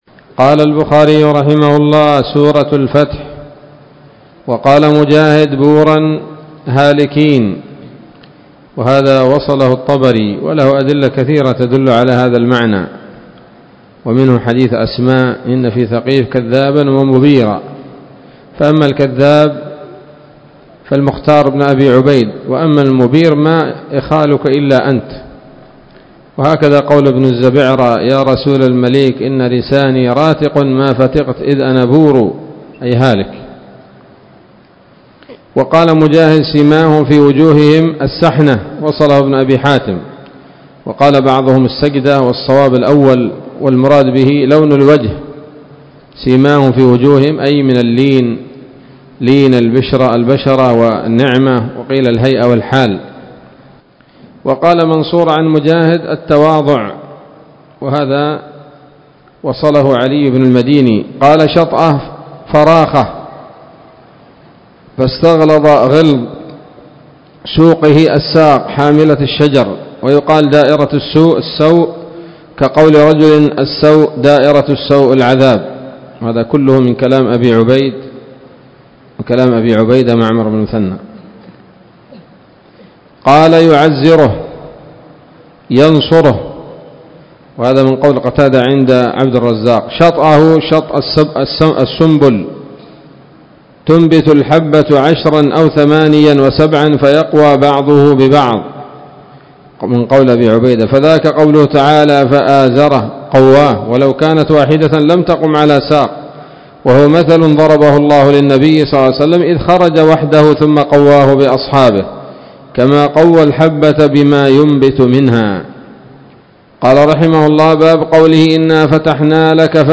الدرس الحادي والثلاثون بعد المائتين من كتاب التفسير من صحيح الإمام البخاري